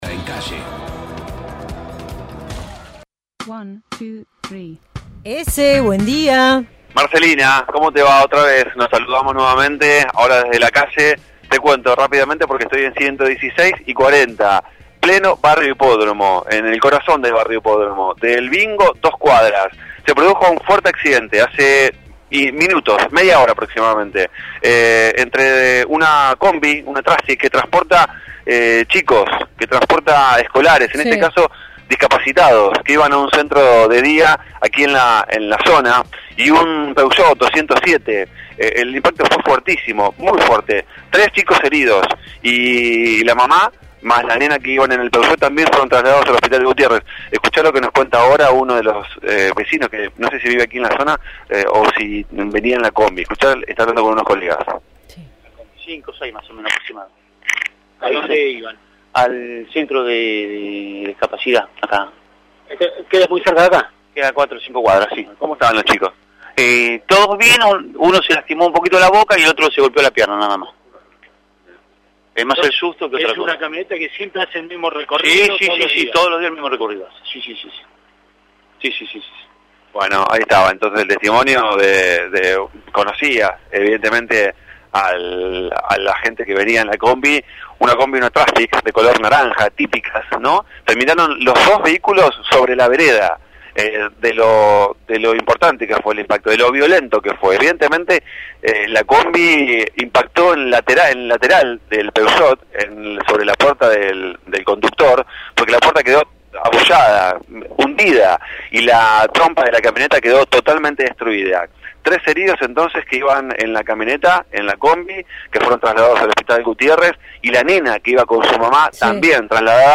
MÓVIL/ Accidente de tránsito en Barrio Hipódromo